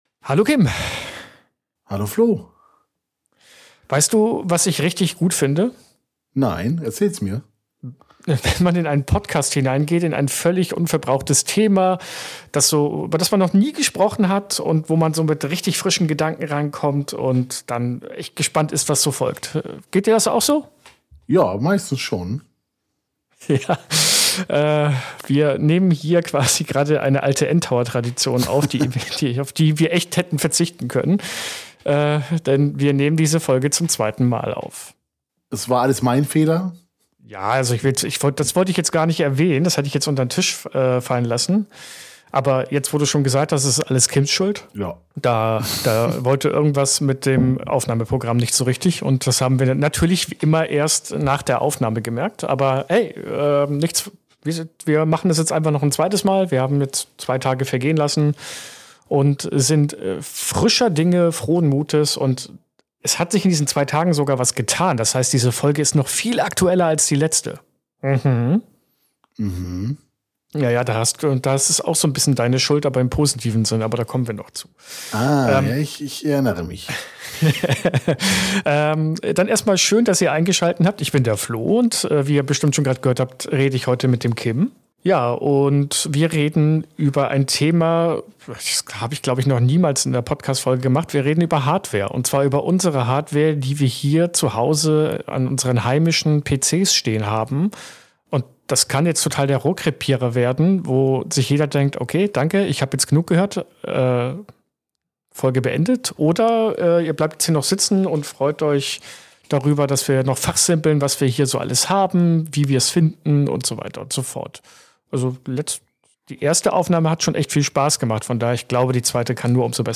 Schon das Setting ist besonders: Die Folge musste wegen eines kleinen Technik-Malheurs zweimal aufgenommen werden – frische Perspektiven inklusive. Statt über Grafikkarten oder Prozessoren zu fachsimpeln, geht’s heute um die Geräte, die man jeden Tag mit den Händen berührt oder stundenlang auf den Kopf schnallt.